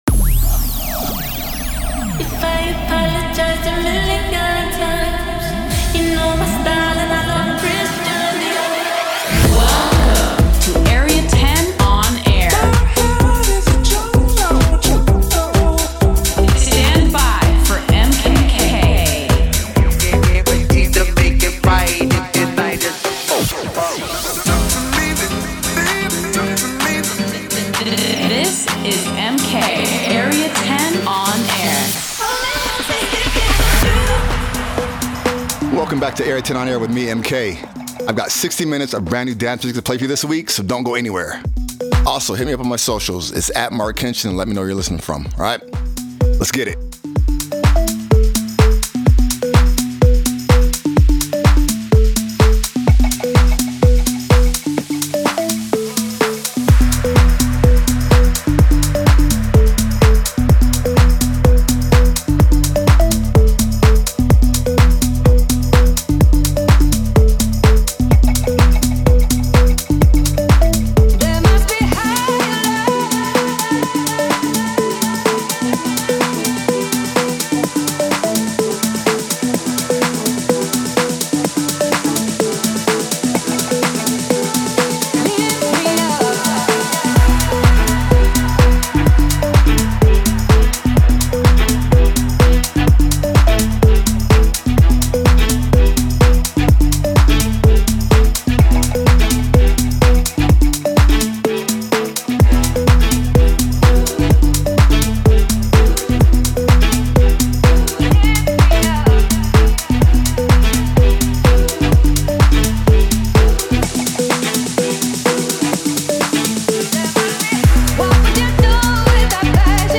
Delving in to house and techno